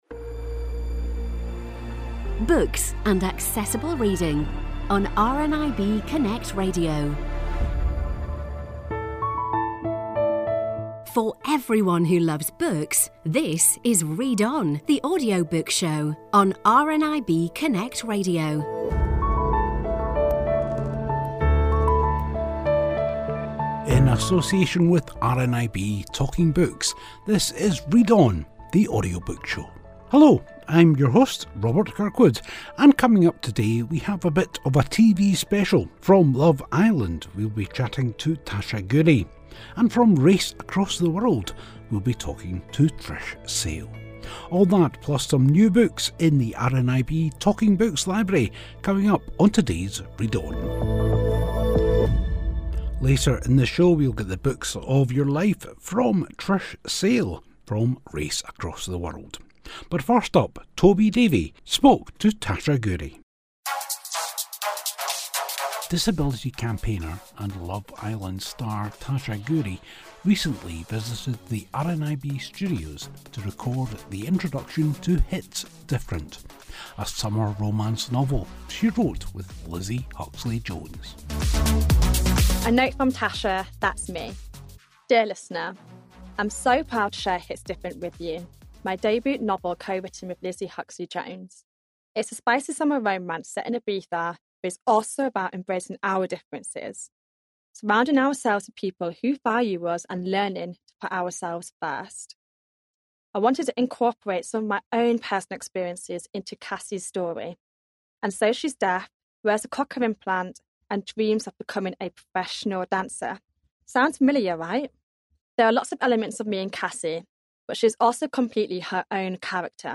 All that plus we listen to four brand new RNIB Talking Books.